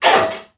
NPC_Creatures_Vocalisations_Robothead [19].wav